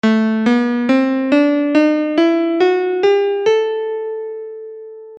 Tuna_Piano.mp3